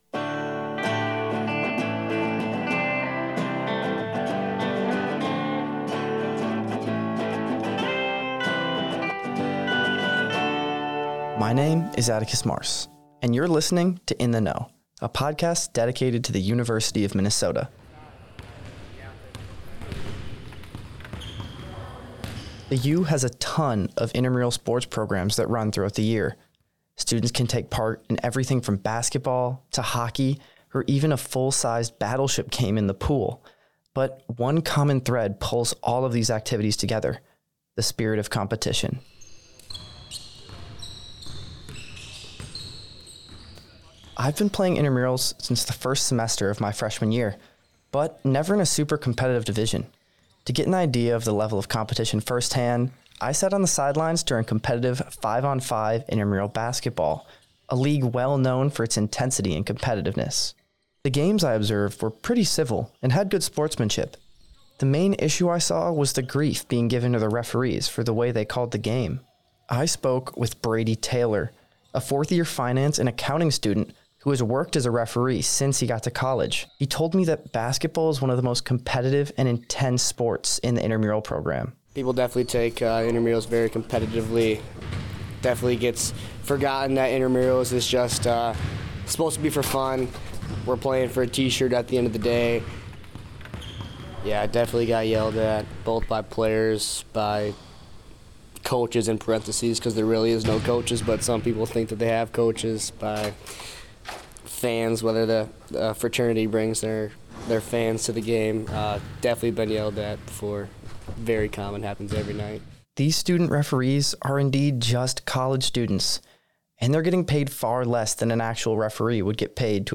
Intramural athletes share how intensity, passion and pride drive games that are supposed to be “just for fun.”